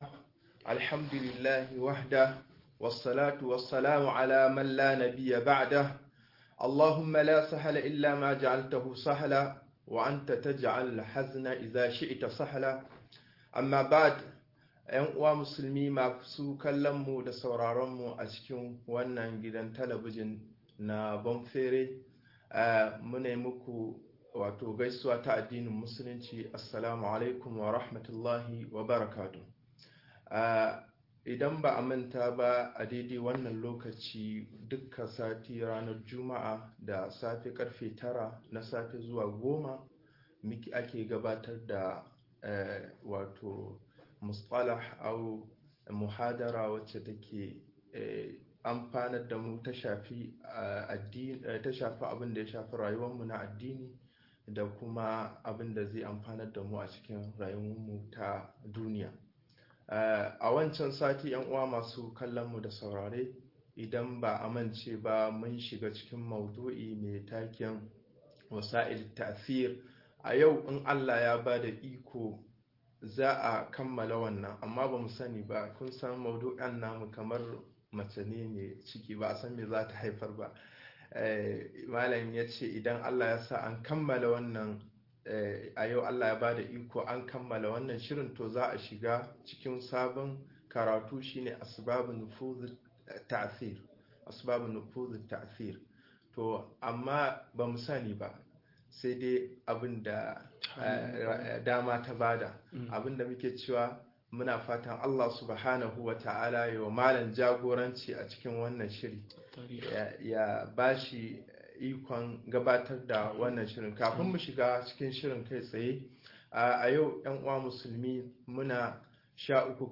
Hanyoyin yin tasiri ga al'umma-02 - MUHADARA